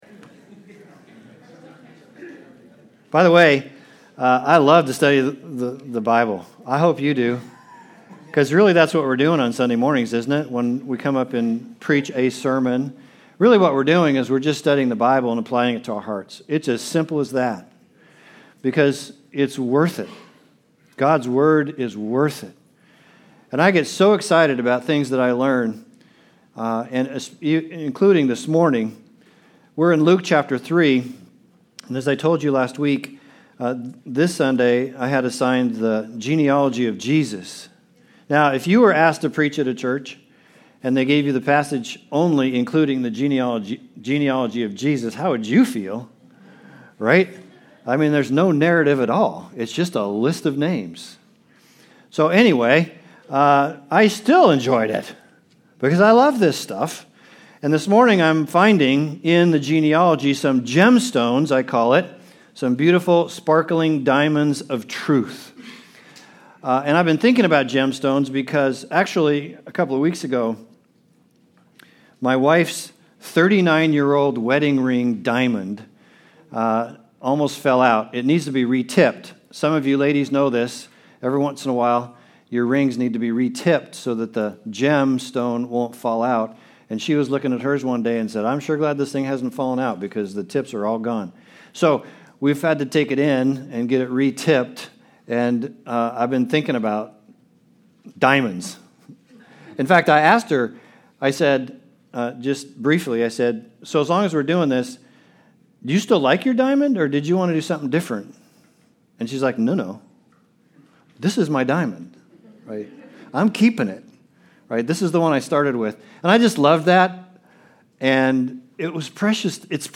Service Type: Sunday Service